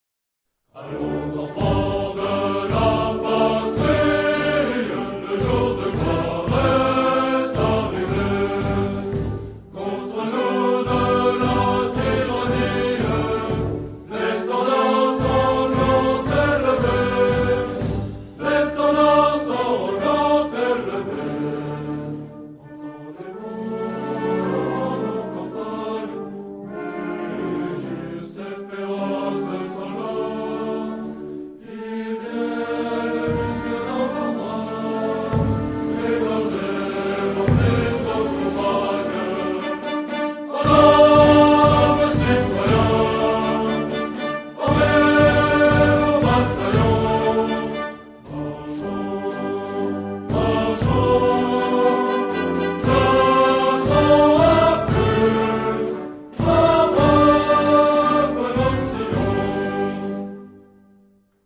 SONNERIES MILITAIRES ADAPTÉES AUX CÉRÉMONIES